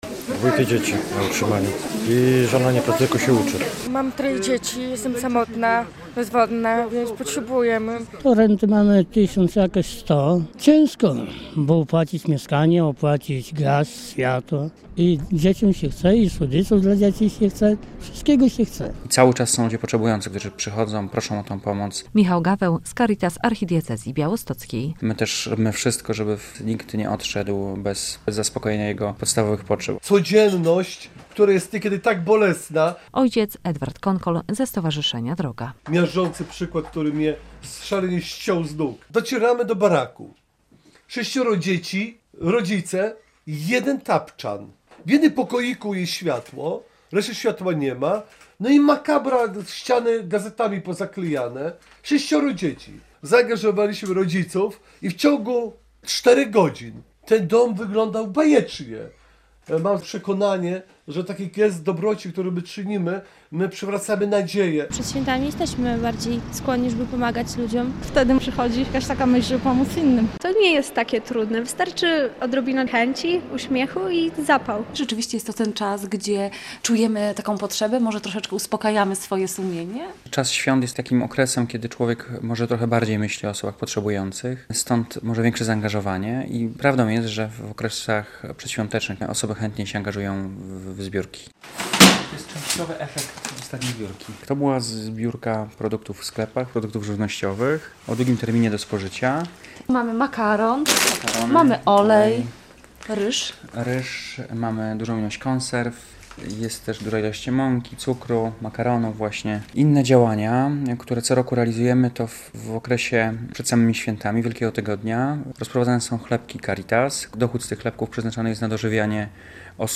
Przedświąteczne akcje charytatywne w regionie - relacja